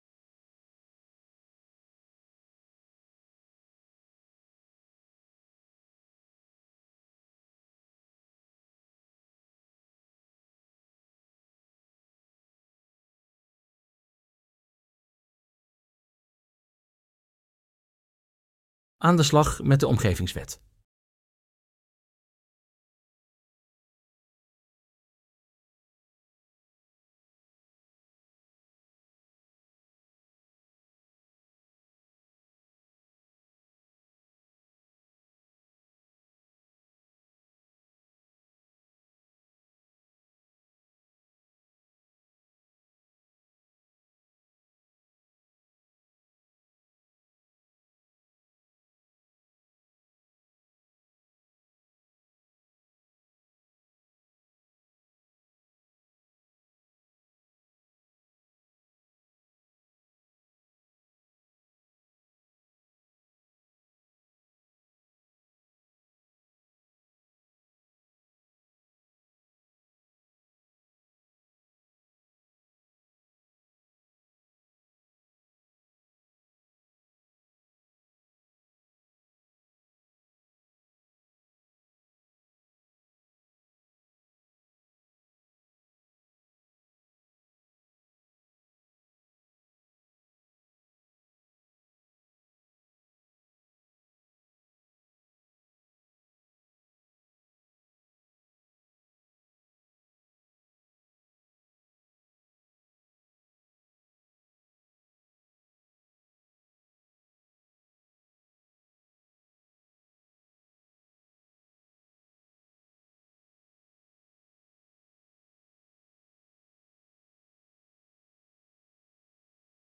Webinar waarin experts in grondeigendom uitleg geven over de 2 soorten kostenverhaal onder de Omgevingswet en over de financiële bijdrage.